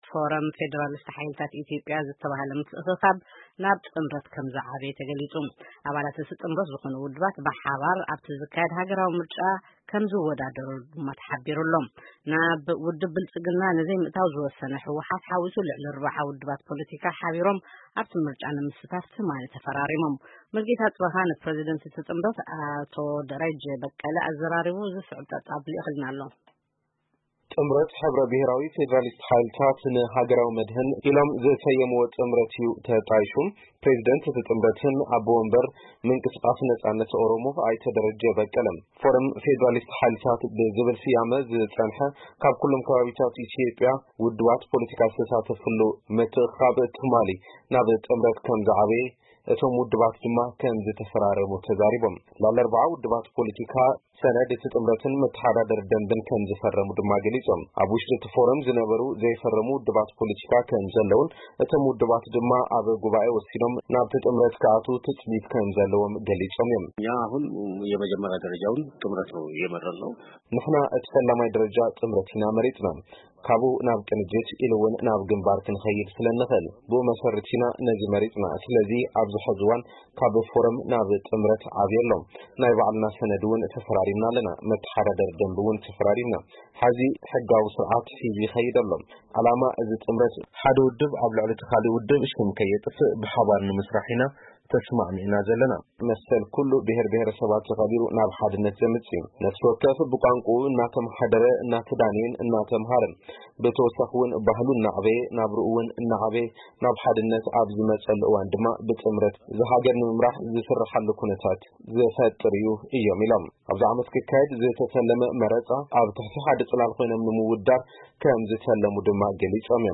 ብምዝርራብ ዝተዳለወ ጸብጻብ ኣብዚ ምስማዕ ይክኣል። 'ፎረም ፌደራሊስት ሓይልታት ኢትዮጵያ' ጥምረት ምምስራቱ ተገሊፁ